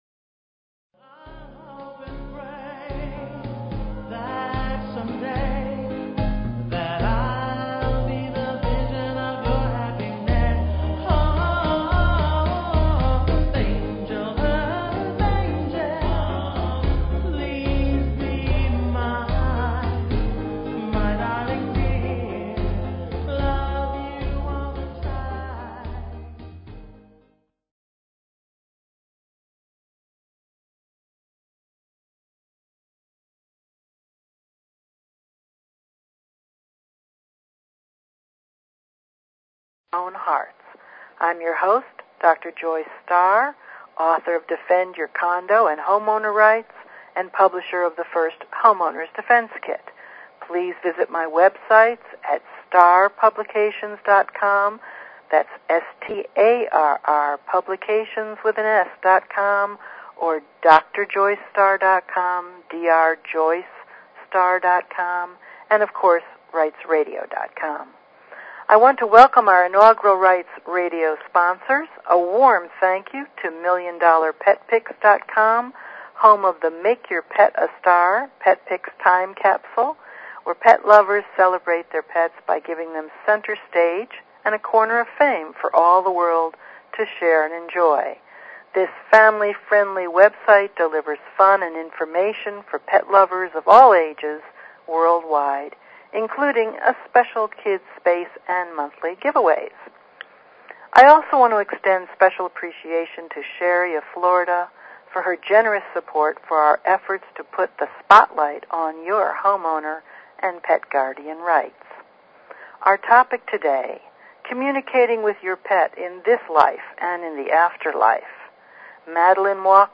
Talk Show Episode, Audio Podcast, Rights_Radio and Courtesy of BBS Radio on , show guests , about , categorized as